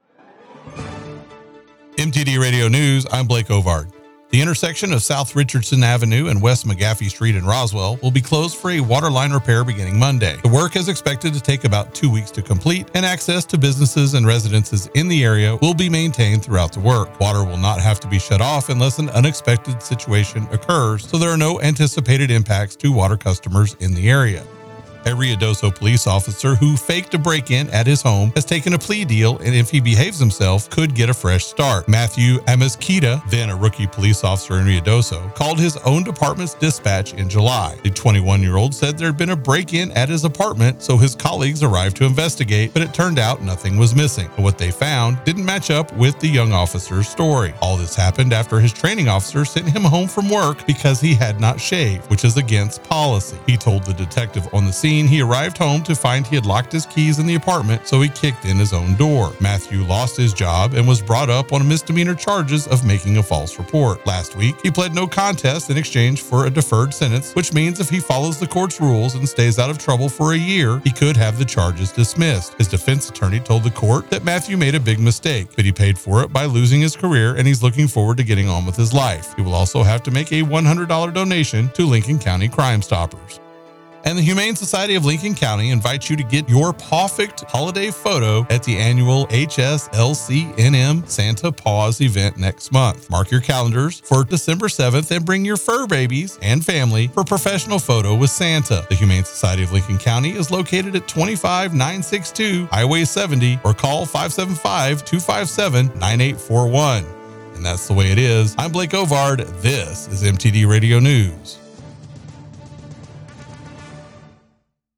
KIDX NEWS RUIDOSO AND LINCOLN COUNTY